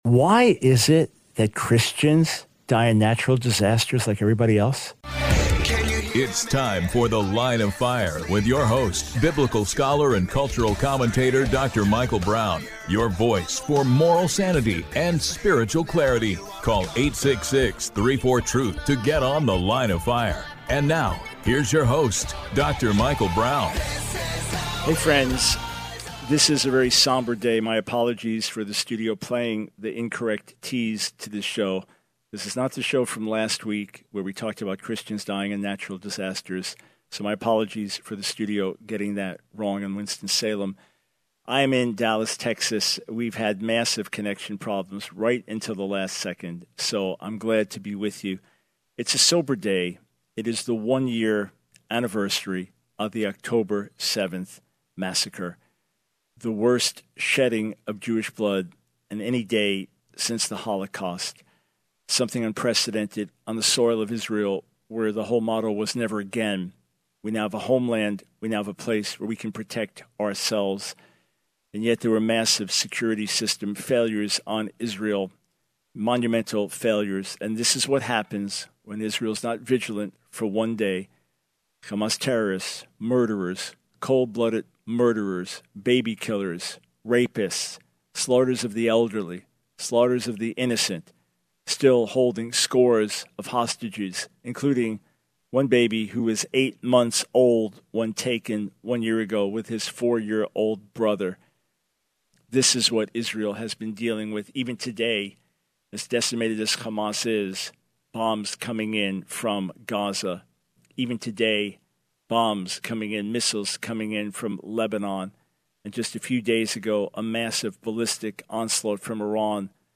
The Line of Fire Radio Broadcast for 10/07/24.